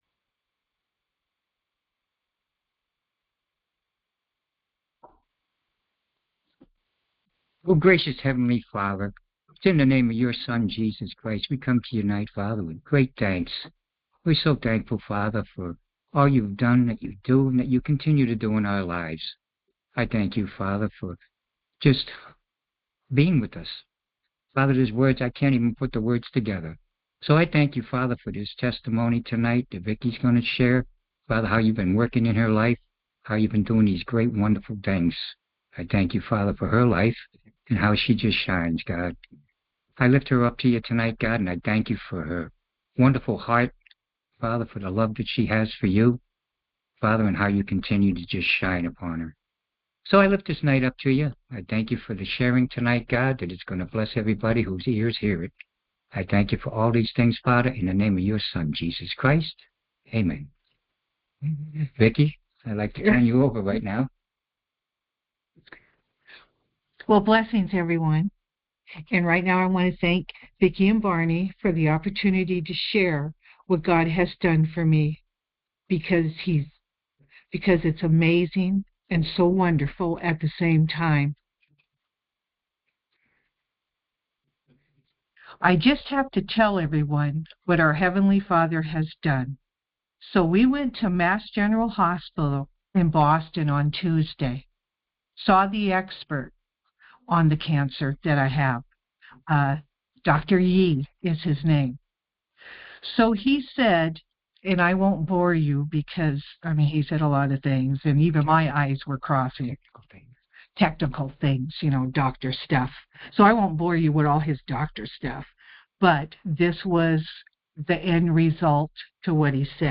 Series: Conference Call Fellowship
1 Peter 2:24 Play the sermon Download Audio